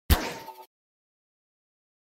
gmod tool gun Meme Sound Effect
gmod tool gun.mp3